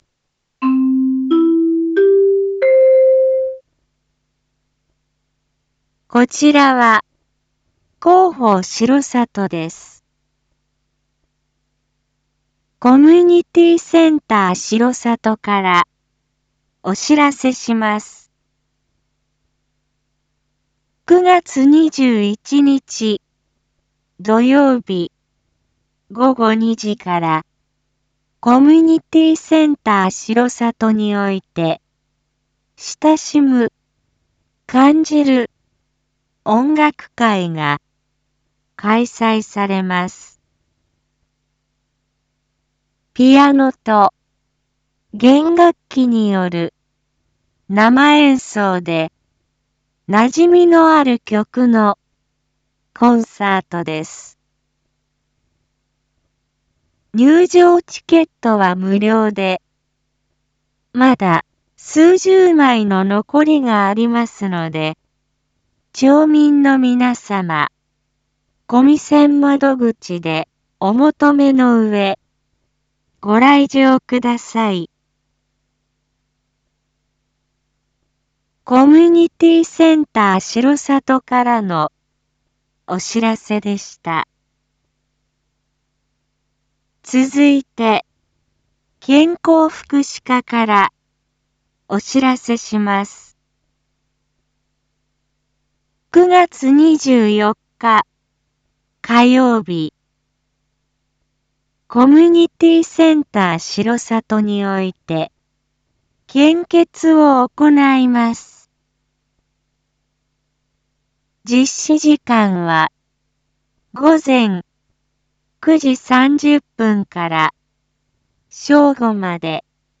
一般放送情報
Back Home 一般放送情報 音声放送 再生 一般放送情報 登録日時：2024-09-20 19:02:35 タイトル：「親しむ・感じる 音楽会」の開催について インフォメーション：こちらは、広報しろさとです。